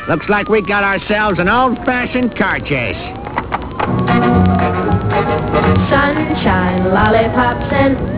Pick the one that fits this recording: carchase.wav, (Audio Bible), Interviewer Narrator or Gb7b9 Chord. carchase.wav